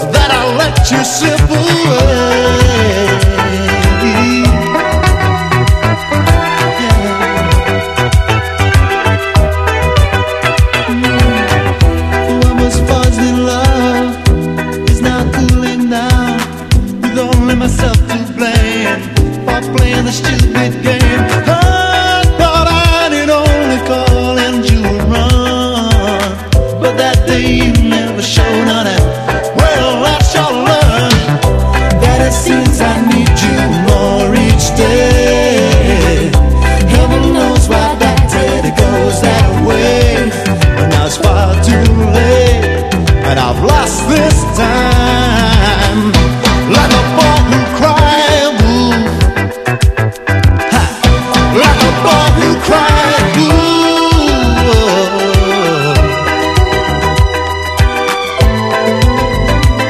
FRENCH POP / OLDIES / GIRL POP
オルガンとキレのいいパーカッション、そしてスキャットも素晴らしいモッド・ナンバー